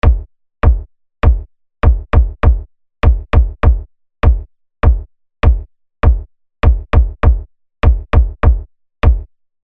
… oder, in Kombination mit dem Click-Modul (Beat the Frog 2):
Apropos Kratzer: Diese haben reichliche Berücksichtigung bei der Grafik gefunden und signalisieren Vitange-Sound mit Patina, was absolut berechtigt ist: Die Punchbox klingt ebenso fett und derb wie alt (im positiven Sinne).